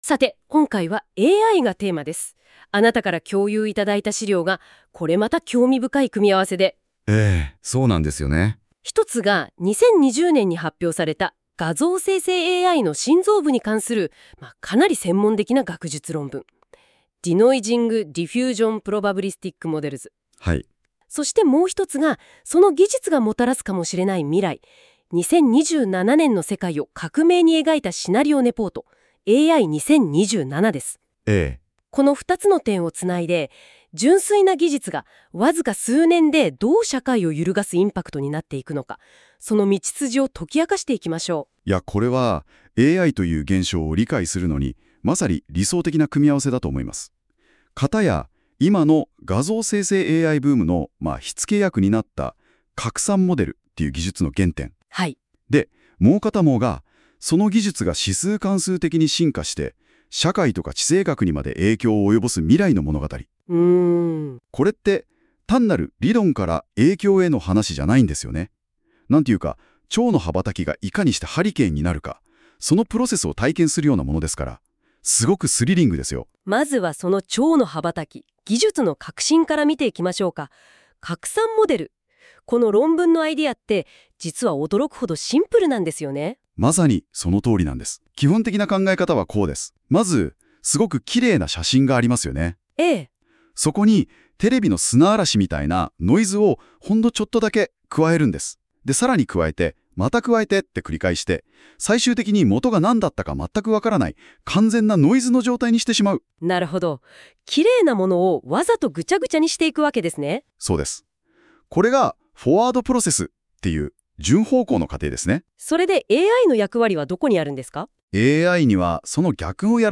シーン 使う機能 通勤電車の中 音声解説を聴く 机に向かえる時間 マインドマップで全体像を確認→レポートで詳細を読む 隙間時間（20分） スライド資料で視覚的に読む 隙間時間（5分） インフォグラフィックで復習 音声解説： 2人のラジオパーソナリティによる音声解説 拡散モデルから2027年の知能爆発へ。.